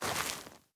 added stepping sounds
Ice_Mono_05.wav